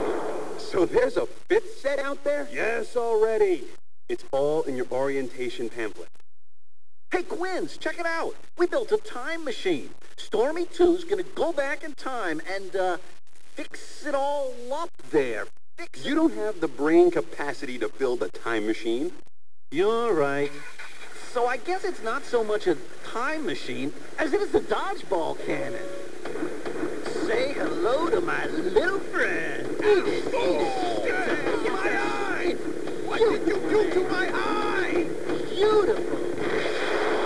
dodgeballcannon.wav